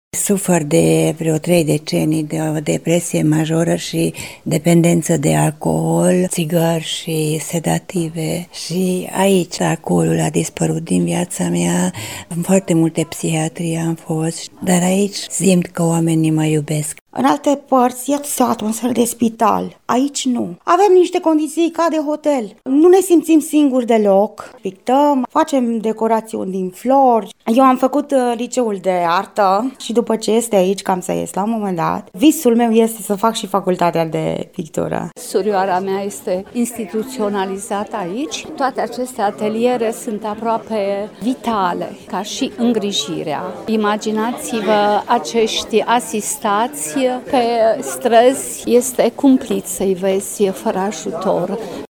brancovenesti-voxuri.mp3